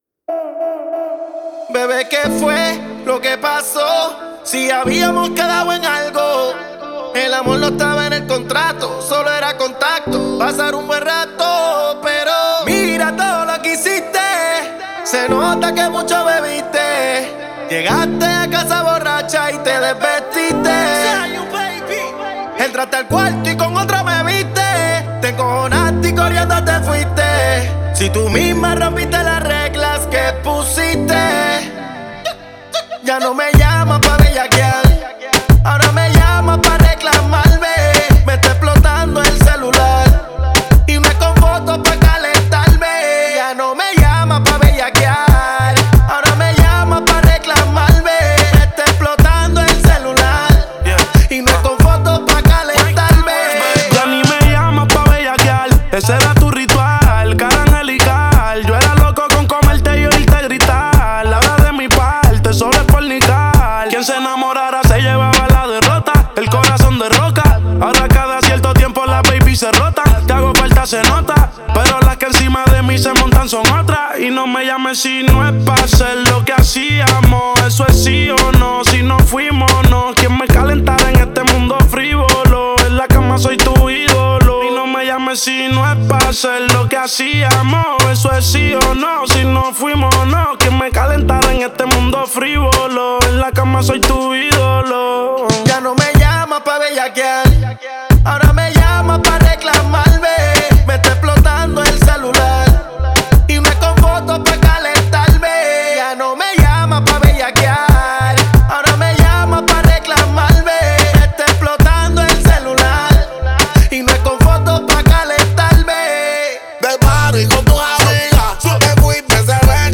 зажигательная реггетон-композиция